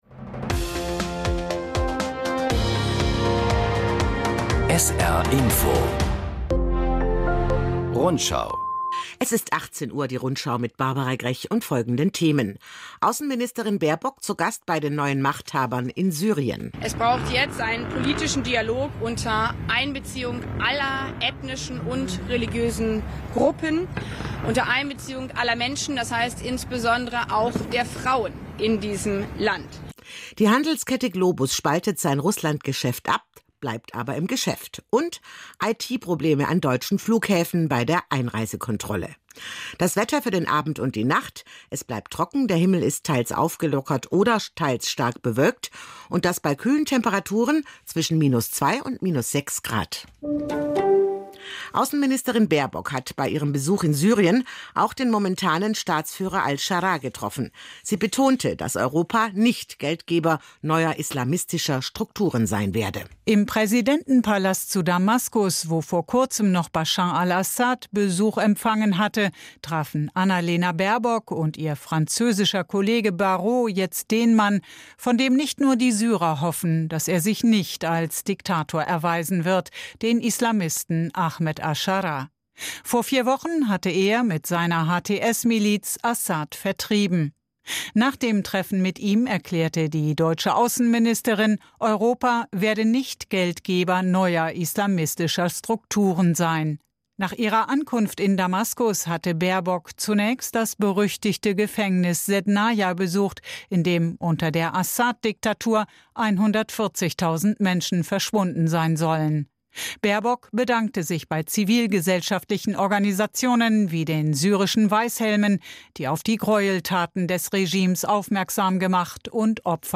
… continue reading 3 επεισόδια # Nachrichten